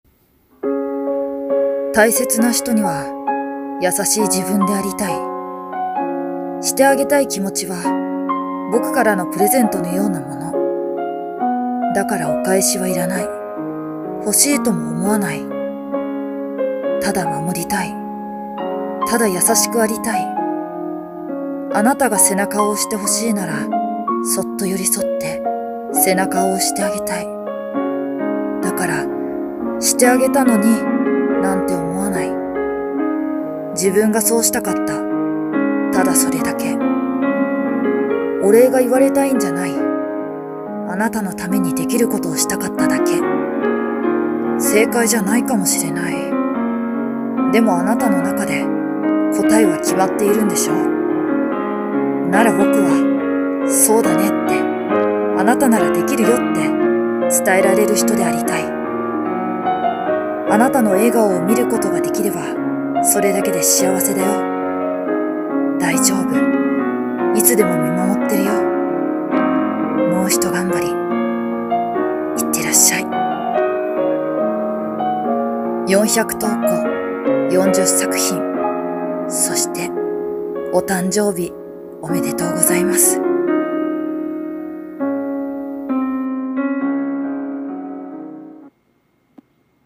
】【声劇】優しさの贈り物。